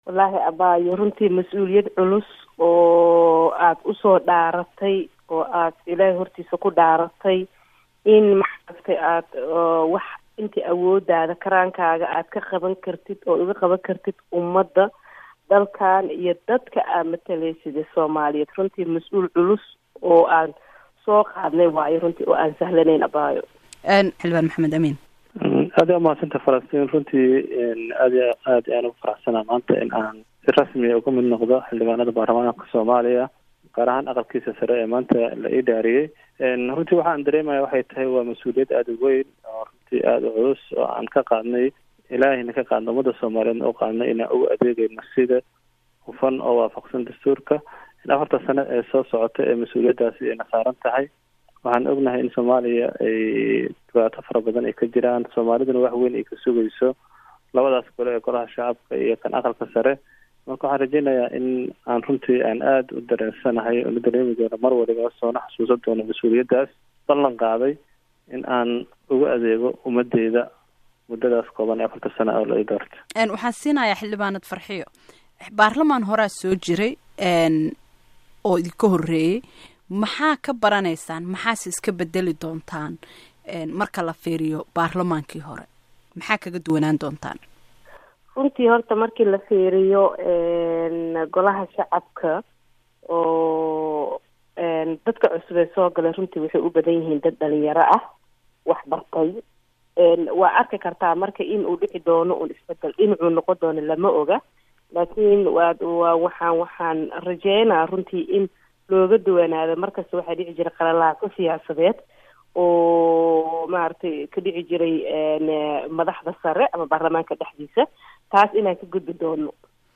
Wareysi: Xildhibaanada cusub